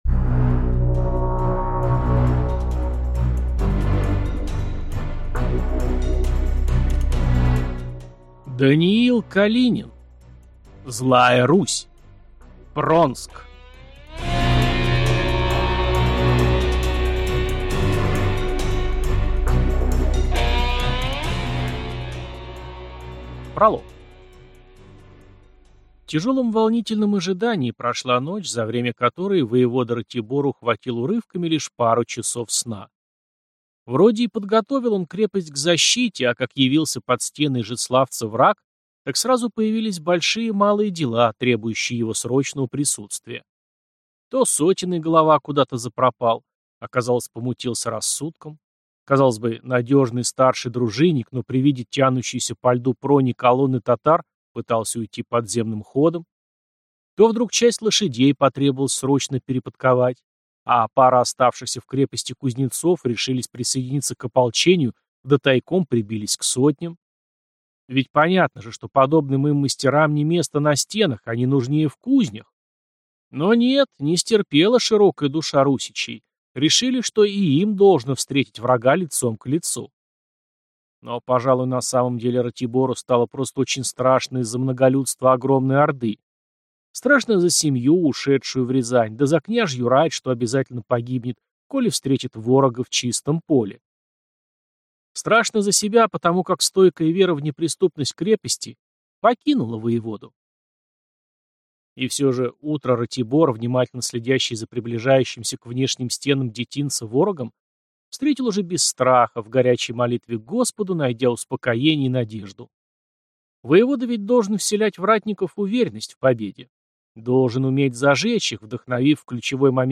Аудиокнига Злая Русь. Пронск | Библиотека аудиокниг